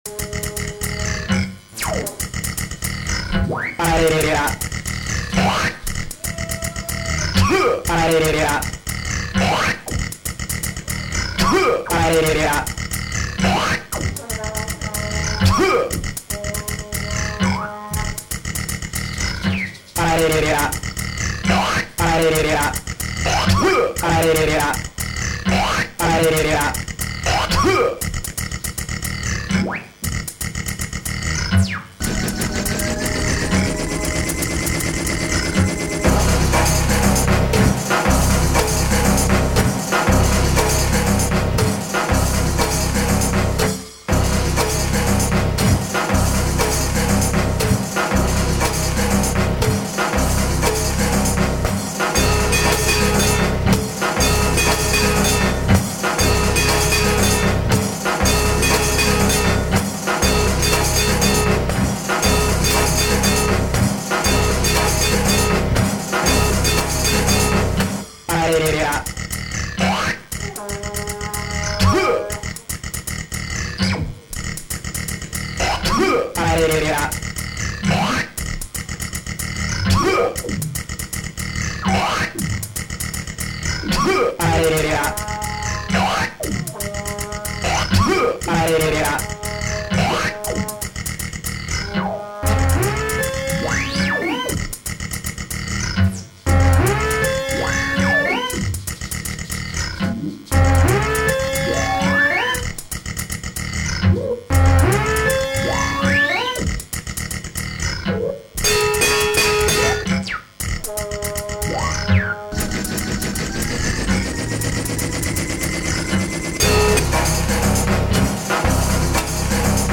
Concerti